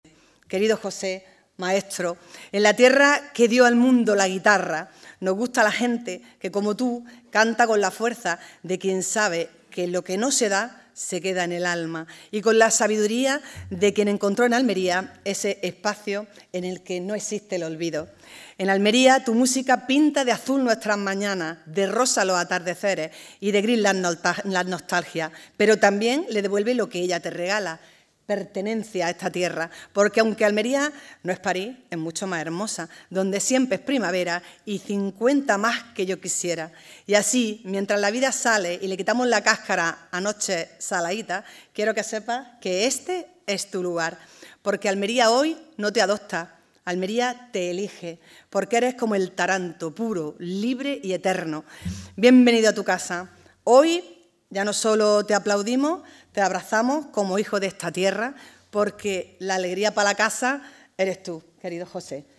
José Mercé ya es un almeriense más tras recoger el título de ‘Hijo Adoptivo’ de la ciudad en un repleto Teatro Apolo
ALCALDESA-JOSE-MERCE.mp3